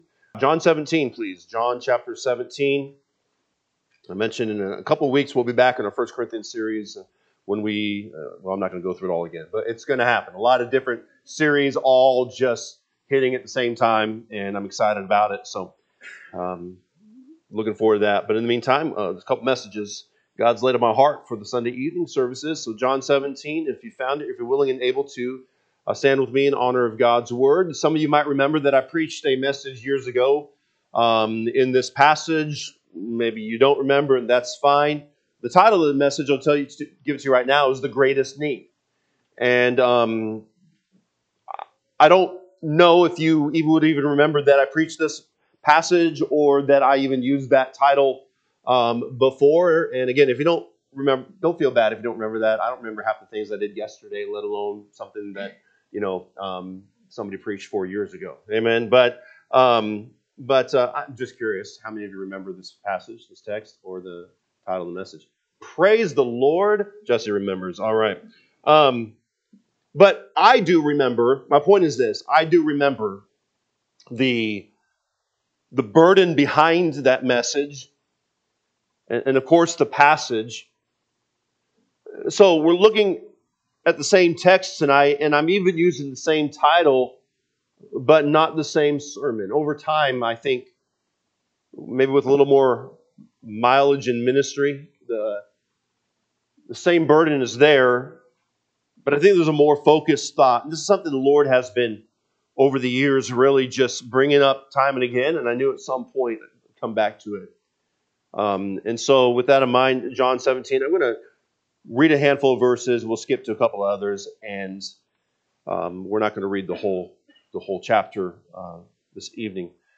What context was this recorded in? March 15, 2026 pm Service John 17:1-5, 9, 20 (KJB) 17 These words spake Jesus, and lifted up his eyes to heaven, and said, Father, the hour is come; glorify thy Son, that thy Son also may glor…